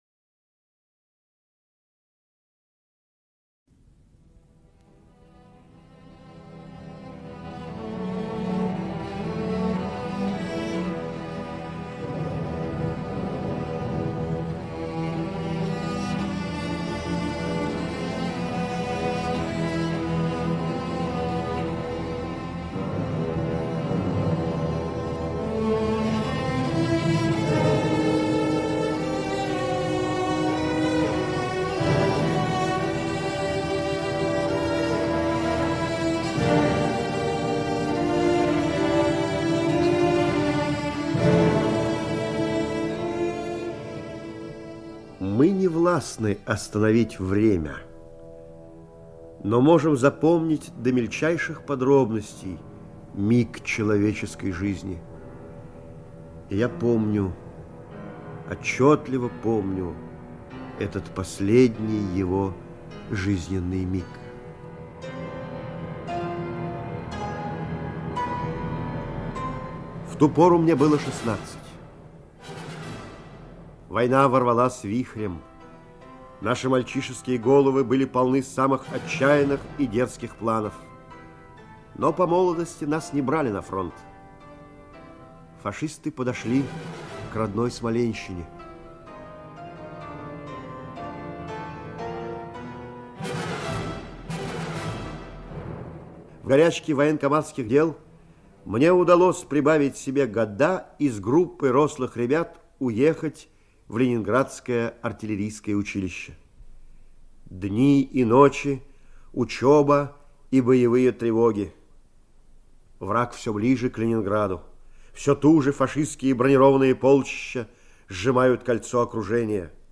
Иван - я, Фёдоровы - мы - аудио рассказ Очкина - слушать